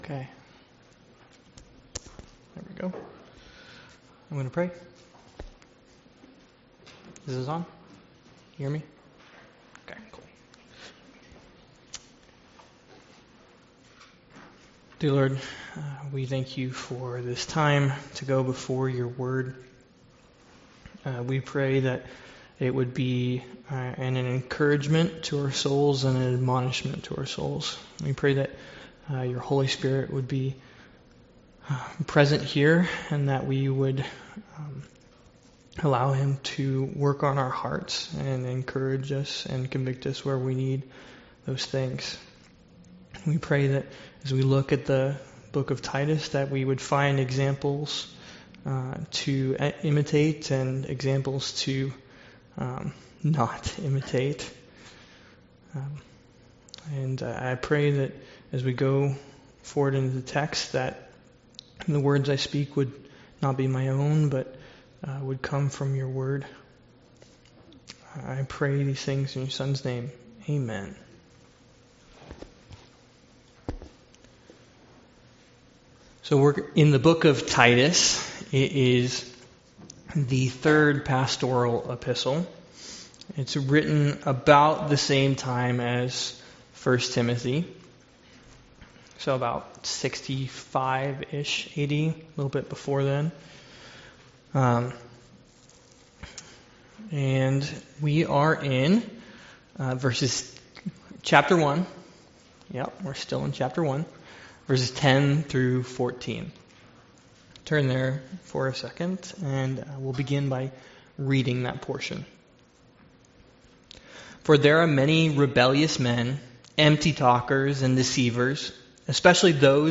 Studies in Exodus Passage: Exodus 15 Service Type: Sunday School « Sorrento